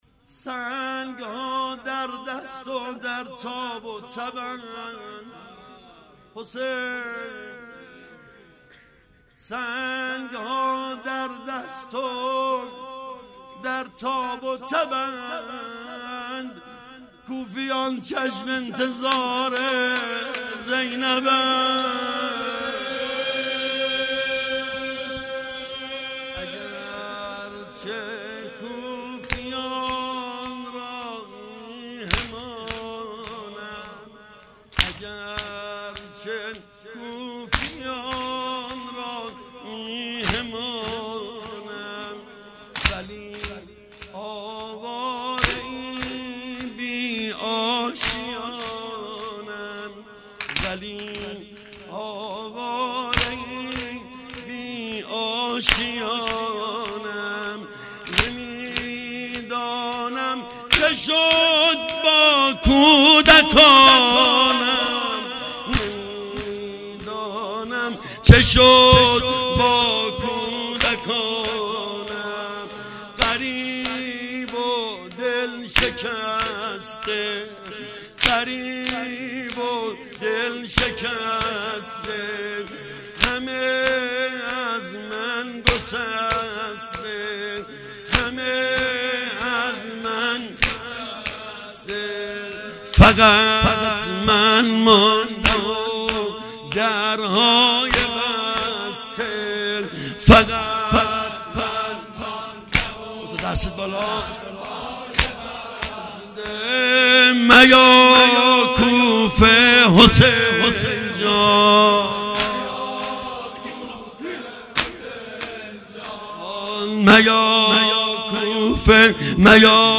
در هیئت الزهرا(س)
روضه